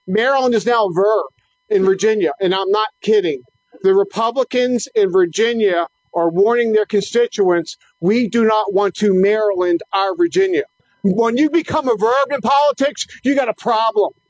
Former Governor Bob Ehrlich joined members of the Maryland Freedom Caucus on Lawyer’s Mall in Annapolis for what was termed a Tax Revolt Rally. The Caucus opposes tax increases being proposed in the General Assembly on Marylanders.